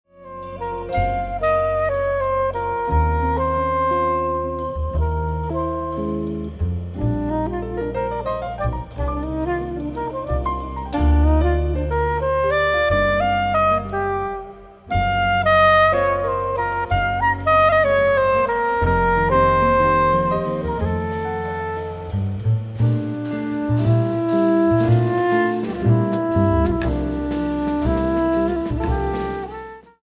saxophone, bassclarinet
piano
guitar
drums, percussion